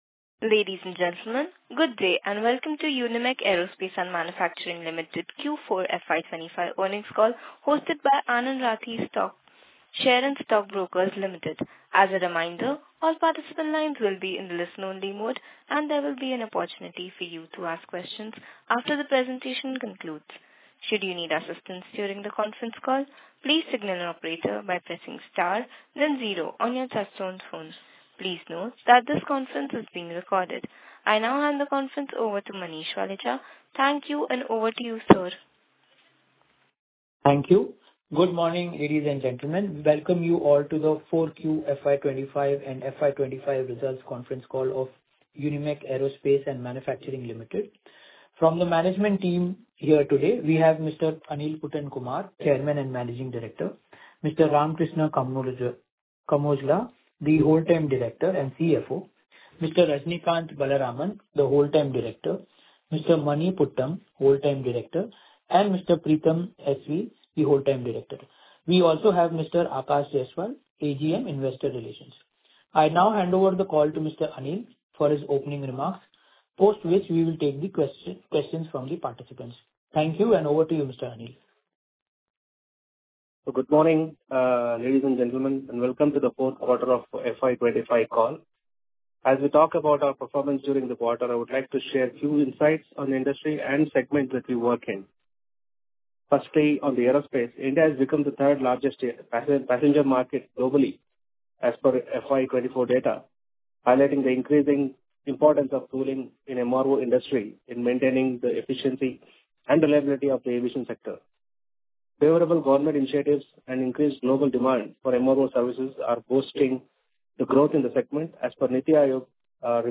Concalls
Earnings-Call-Recording-Q4.mp3